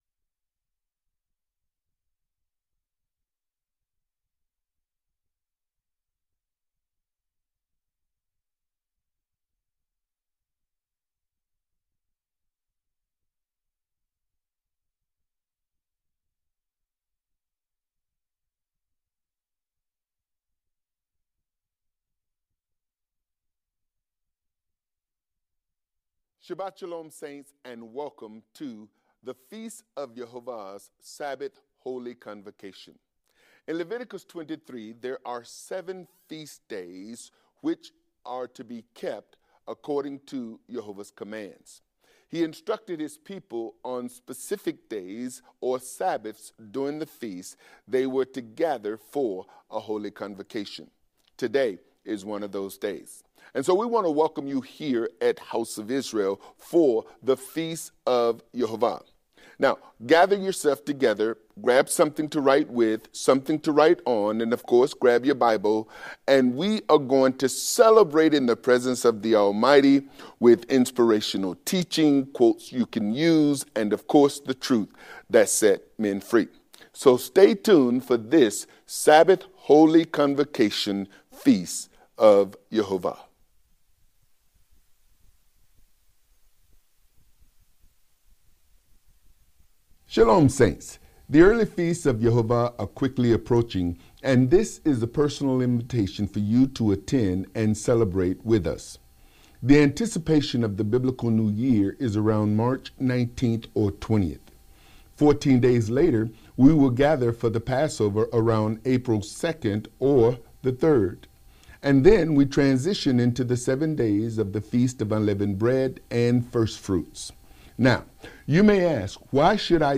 Feast-of-7th-Day-Unleavened-Bread-Service_-The-Bread-of-Supernatural-Deliverance.mp3